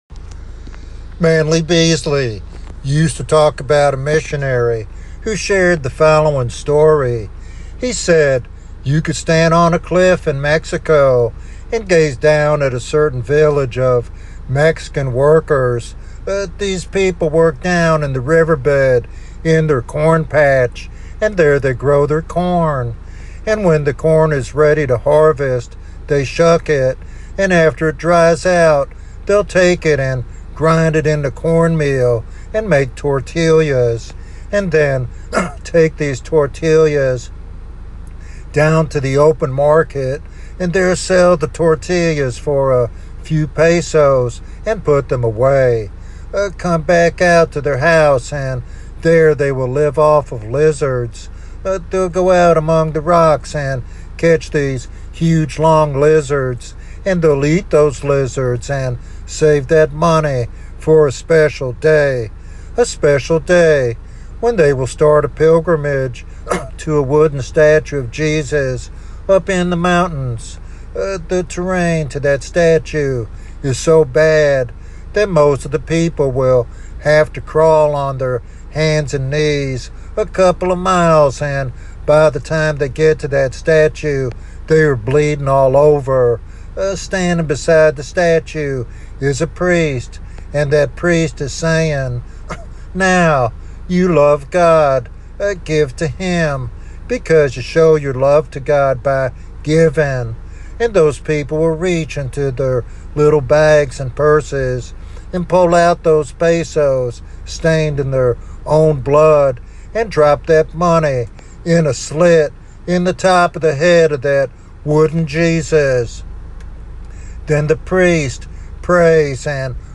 This evangelistic sermon urges a heartfelt response to the gospel and a surrender to the real Christ.